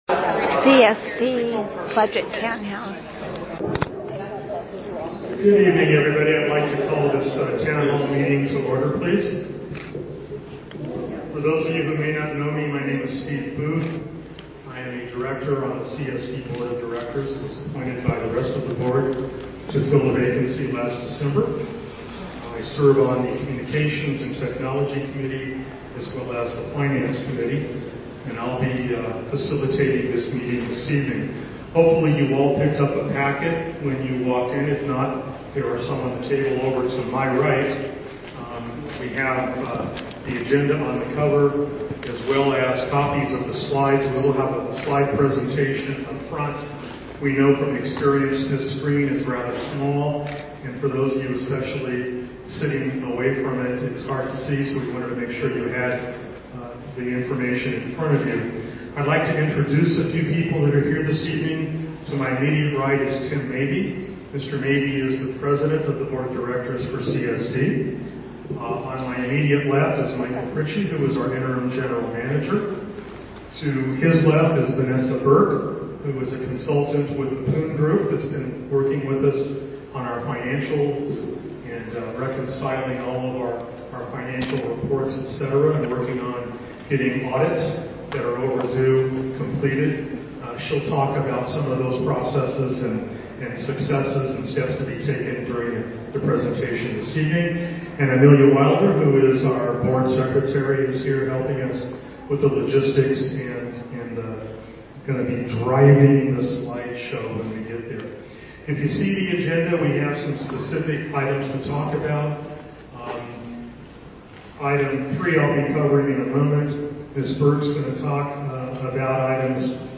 Prop 218 Town Hall Meeting